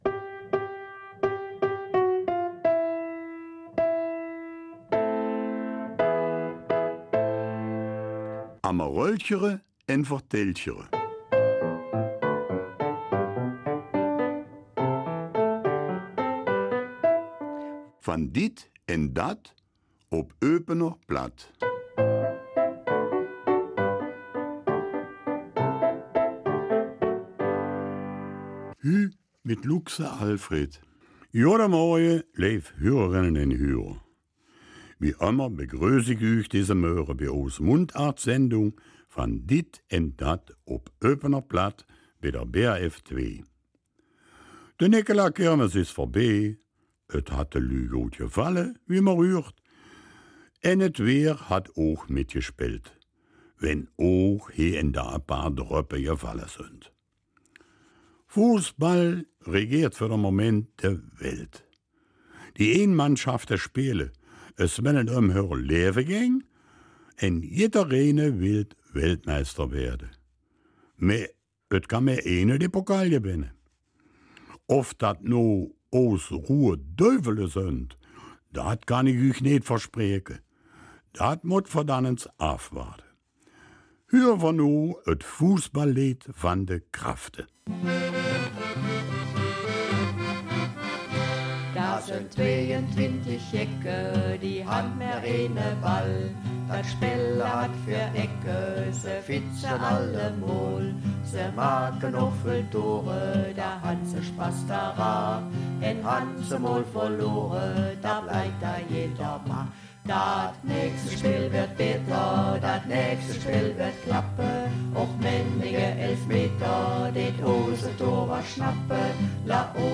Eupener Mundart
Die Sendung ist zu hören auf BRF2 von 9 bis 9:30 Uhr, in der Wiederholung um 19 Uhr und anschließend hier nachzuhören.